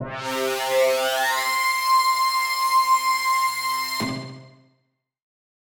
Index of /musicradar/future-rave-samples/Poly Chord Hits/Straight
FR_ProfMash[hit]-C.wav